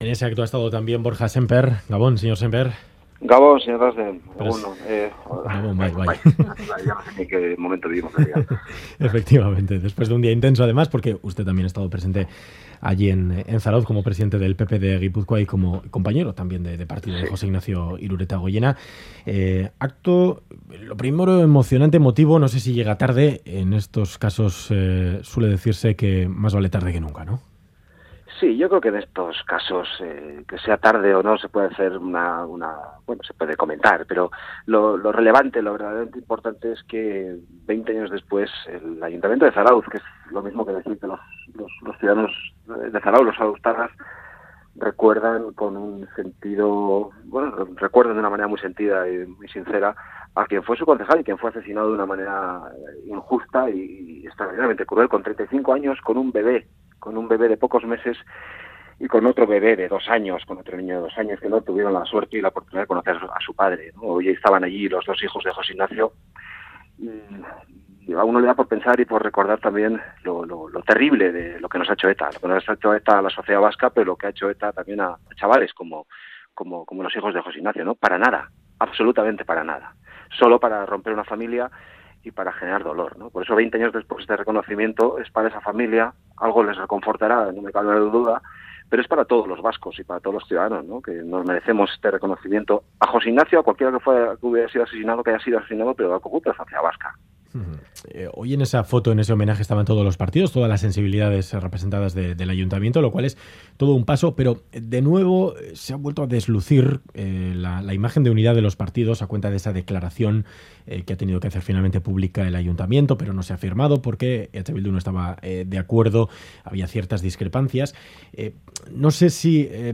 Audio: Entrevista en Ganbara al presidente del Partido Popular de Gipuzkoa, Borja Semper, tras el homenaje en Zarautz a José Ignacio Iruretagoyena, asesinado por ETA hace 20 años.